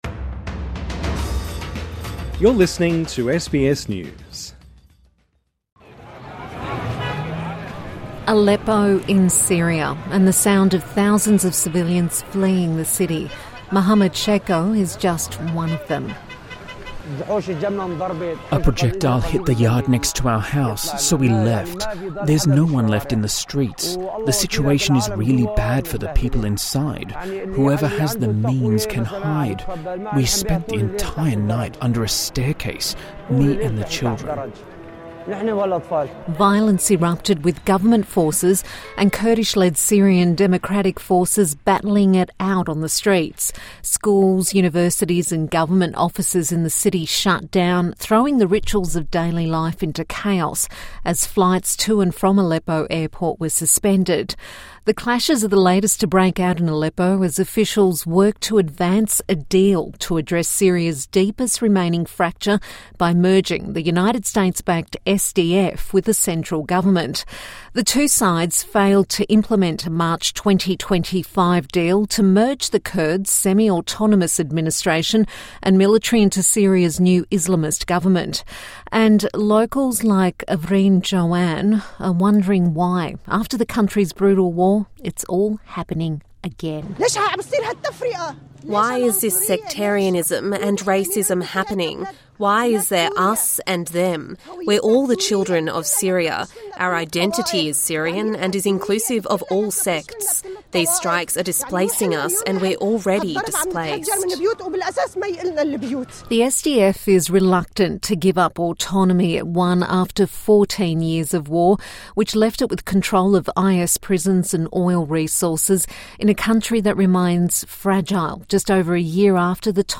TRANSCRIPT: Aleppo in Syria, and the sound of thousands of civilians fleeing the city.